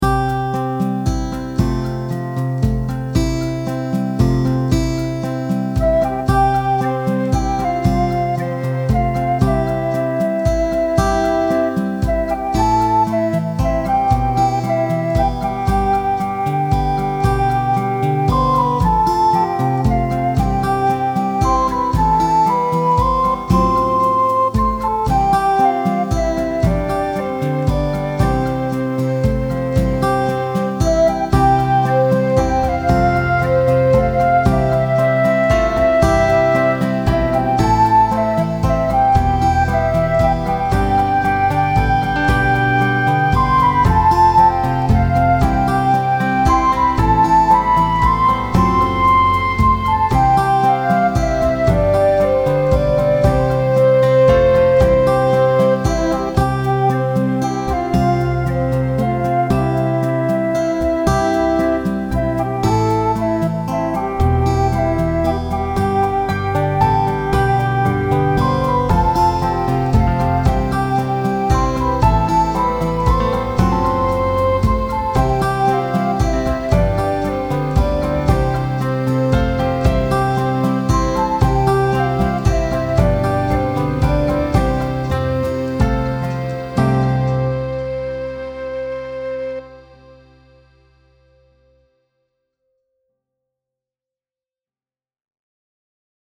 mp3 backing